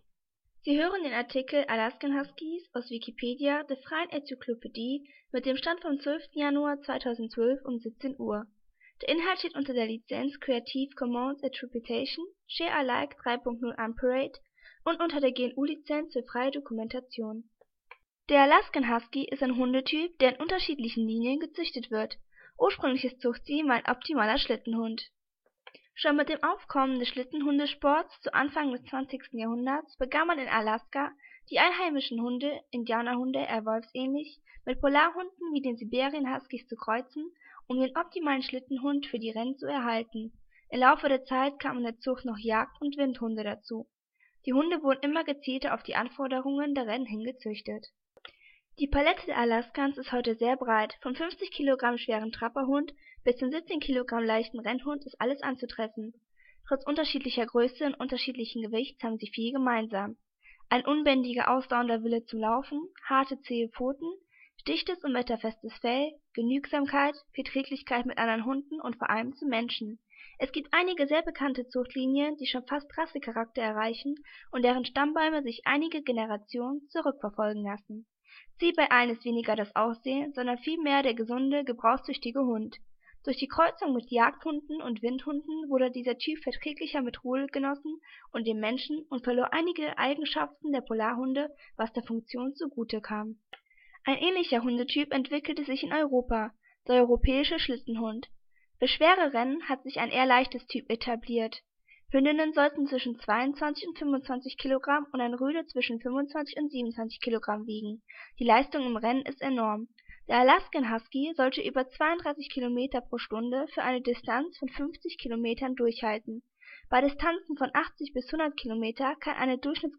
Mehr Informationen zur gesprochenen Wikipedia